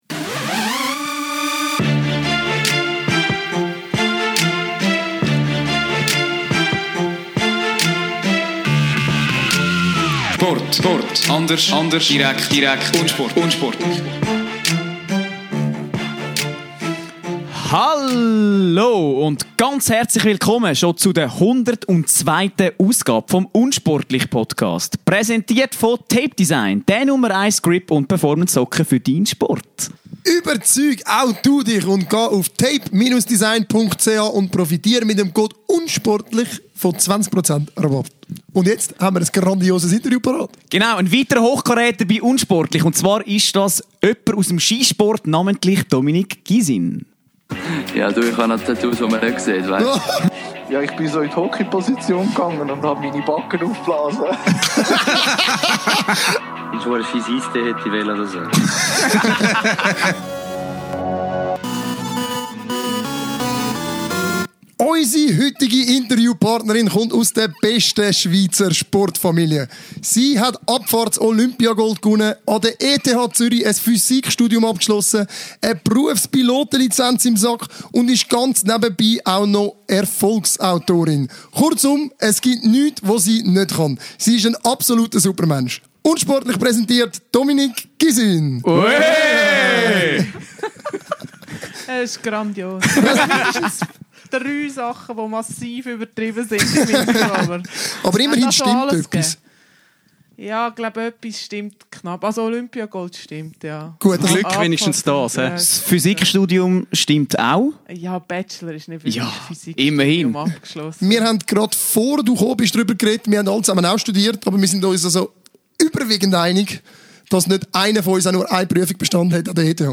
22.05.2019 – Für Episode #102 nahm eine absolute Koryphäe des Schweizer Sports im unsportlichen Keller Platz – Dominique Gisin!